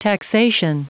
Prononciation du mot taxation en anglais (fichier audio)
Prononciation du mot : taxation